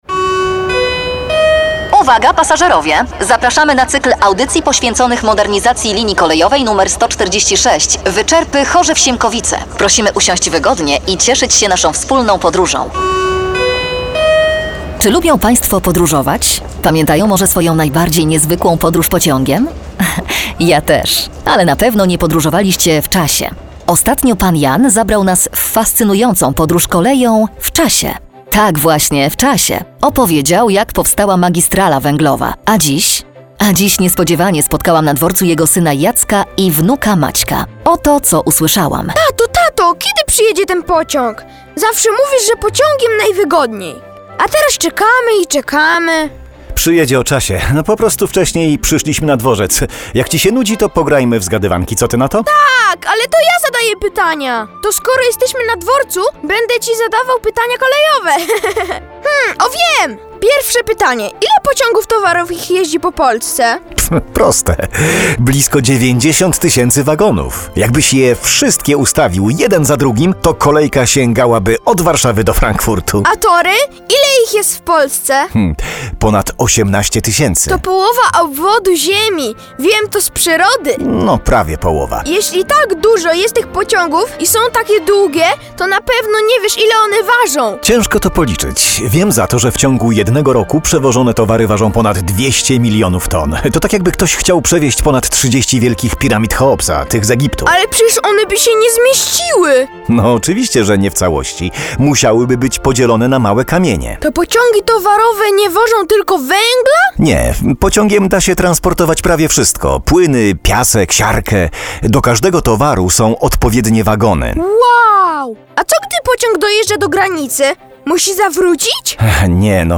Audycje radiowe "Kolejowa podróż w czasie" cz. 2 - listopad 2017 r.